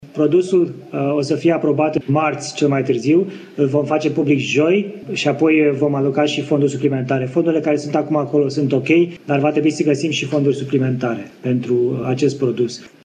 Vor fi măsuri asemănătoare celor pentru întreprinderile mici și mijlocii, a anunțat ministrul de Finanțe, Florin Cîțu, înaintea ședinței de Guvern de astăzi.